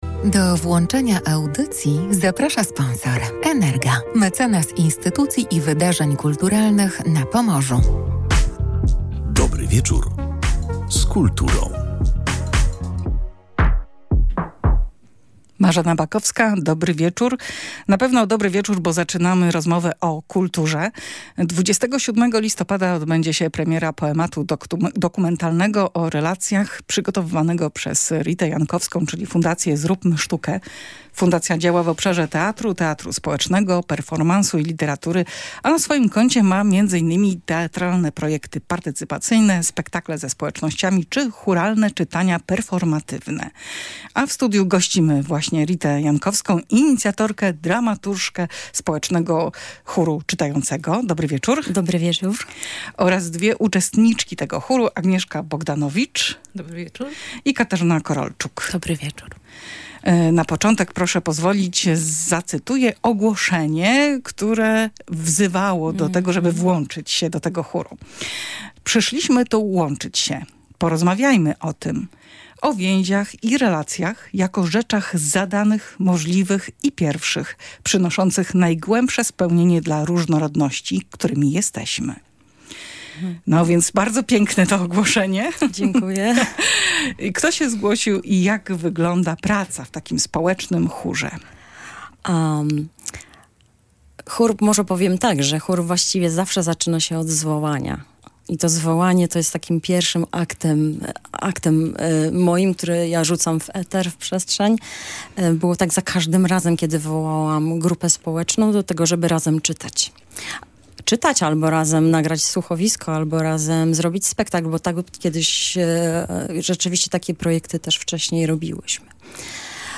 podczas audycji na żywo